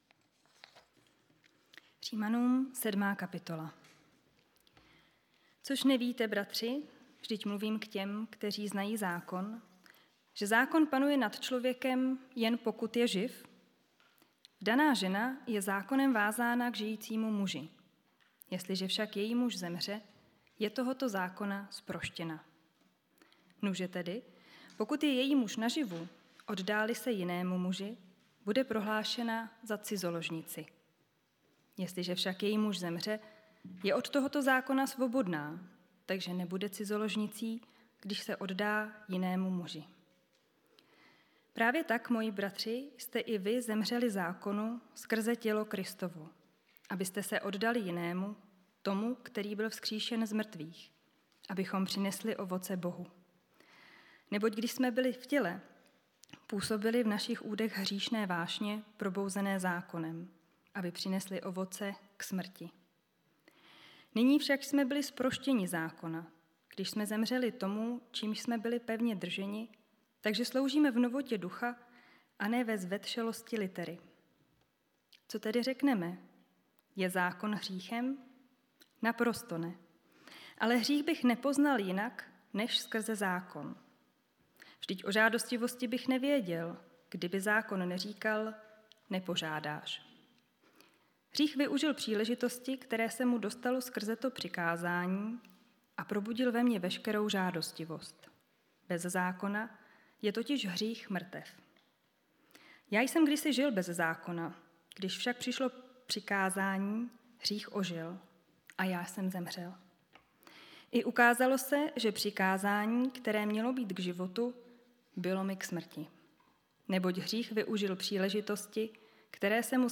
Letní série kázání ZAJATCI NADĚJE | Římanům 7